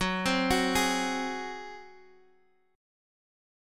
Gbsus2b5 chord